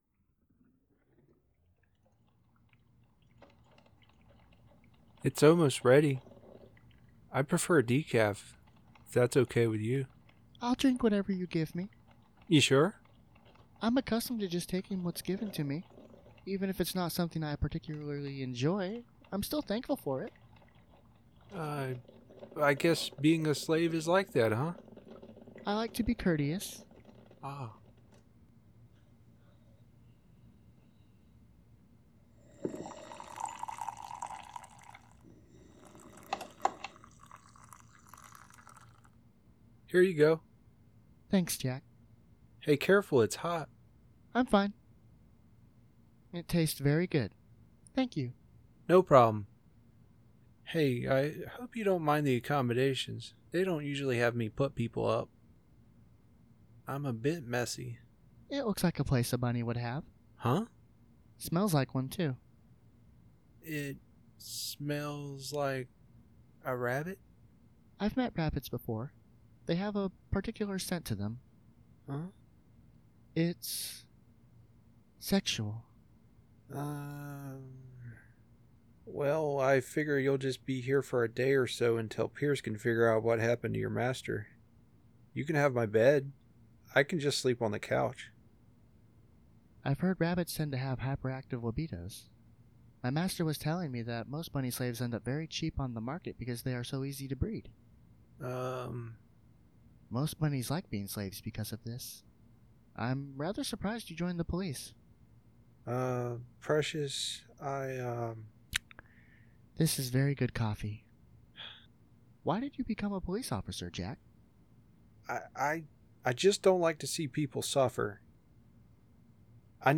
Audio Drama